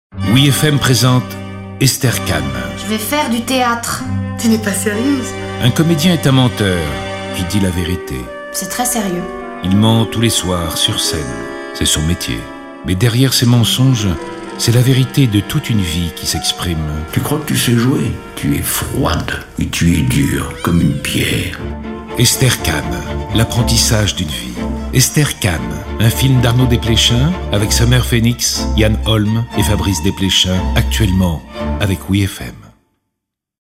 Bande-annonce du film